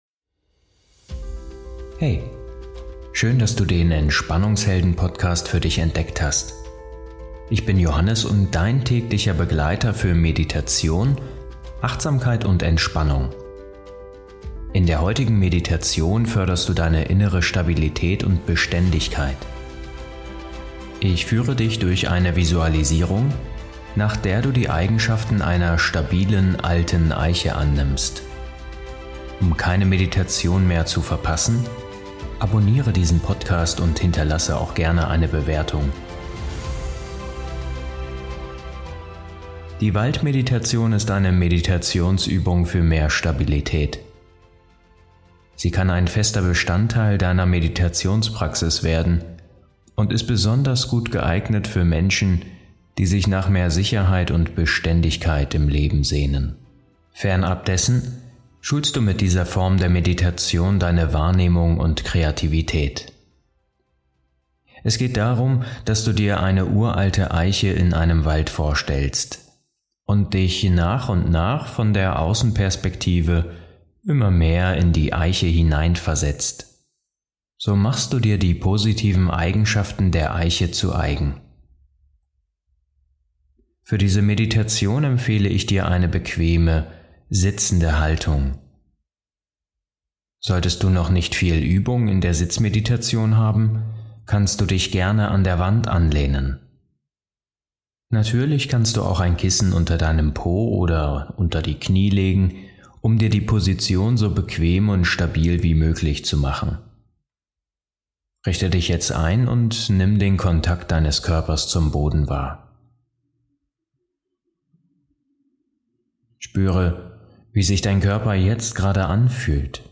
Begleitet von sanfter Musik, lädt dich diese Meditation ein, mit deiner Vorstellungskraft neue Kraft und Balance in dein Leben zu bringen.